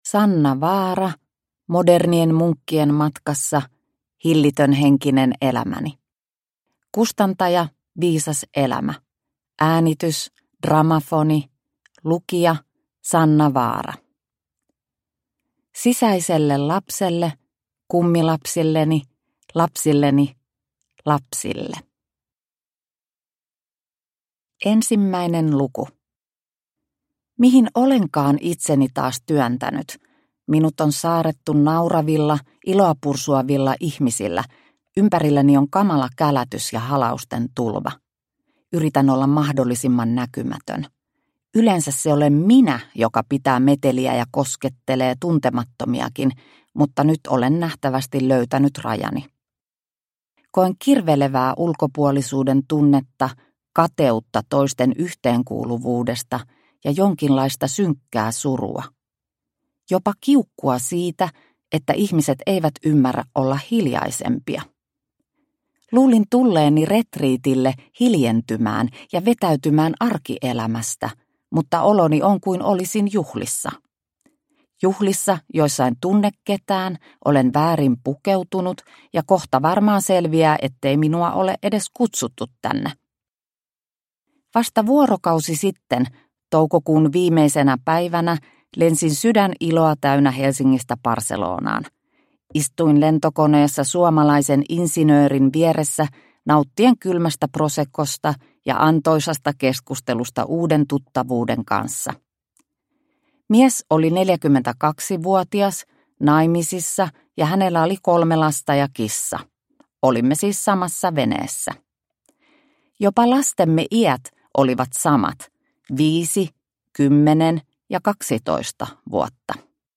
Modernien munkkien matkassa – Ljudbok – Laddas ner